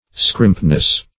Scrimpness \Scrimp"ness\, n. The state of being scrimp.
scrimpness.mp3